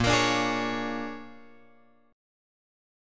A# 7th Sharp 9th